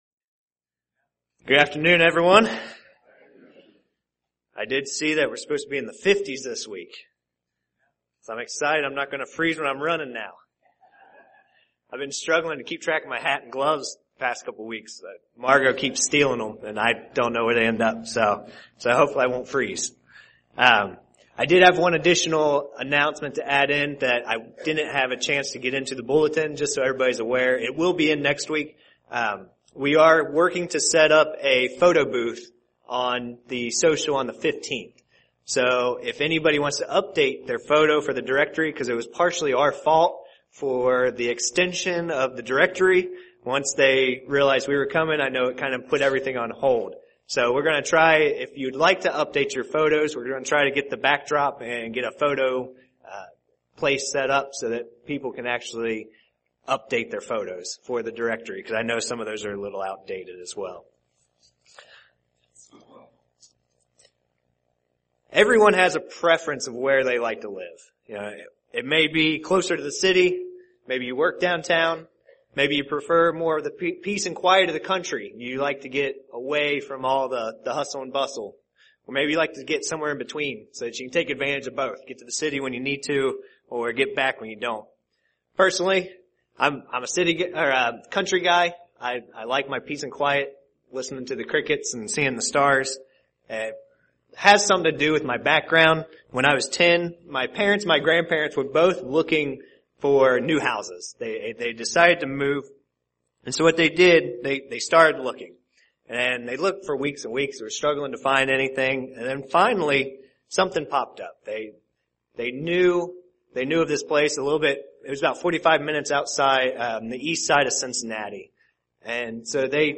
Sermons
Given in Indianapolis, IN